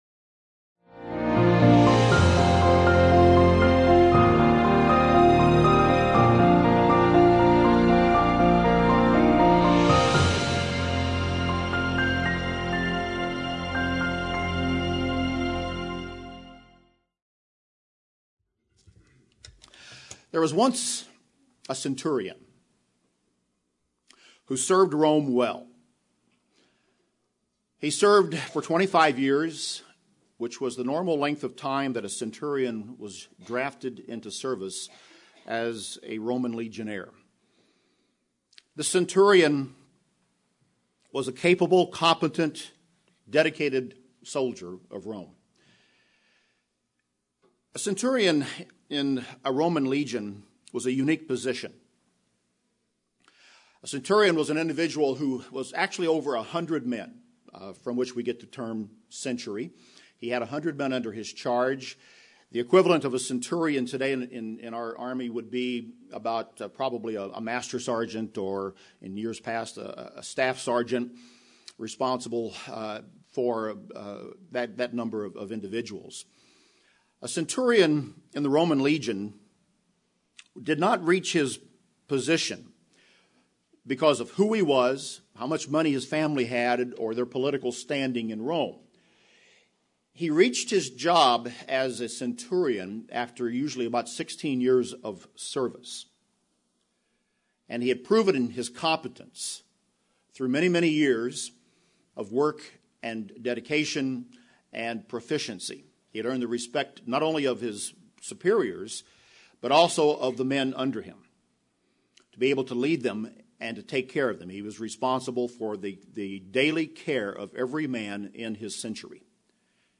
This sermon compares the story of Cornelius to the road that each of us travels to redemption by the blood of Jesus Christ.